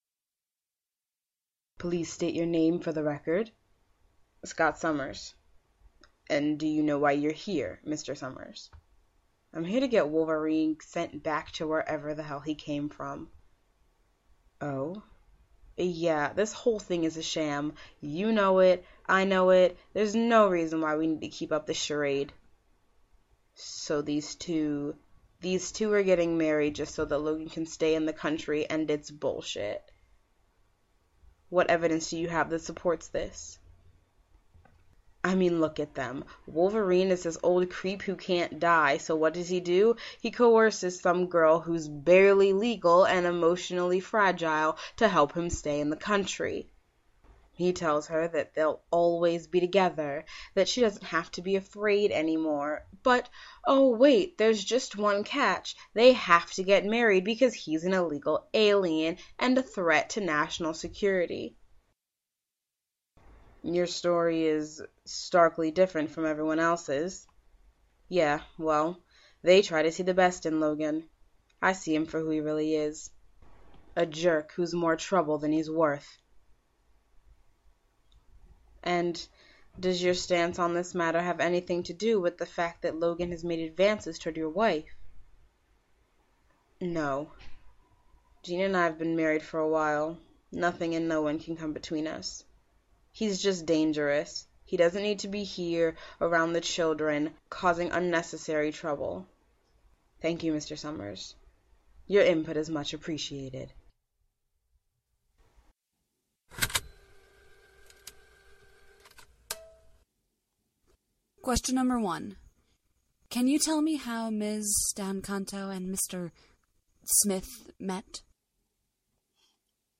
Interviews for the Witnesses || Duration: 00:02:56
Interviews for the Couple || Duration: 00:01:50